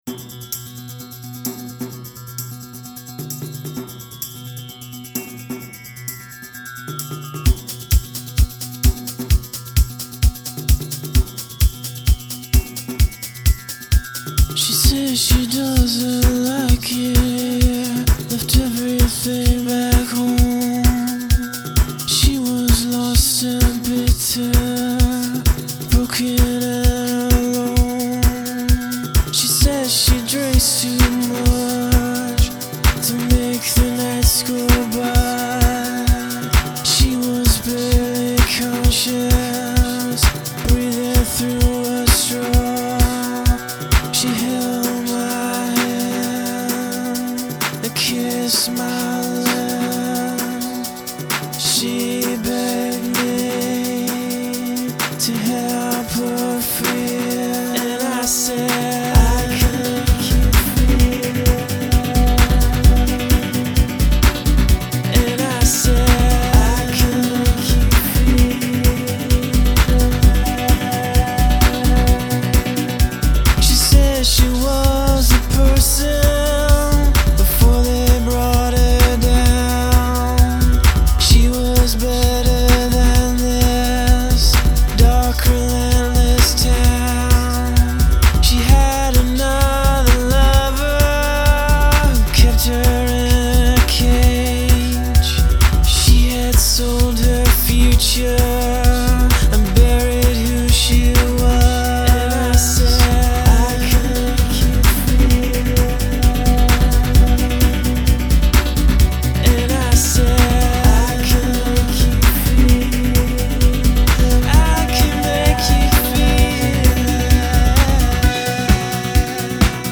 Indietronica